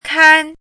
chinese-voice - 汉字语音库
kan1.mp3